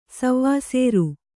♪ savvāsēru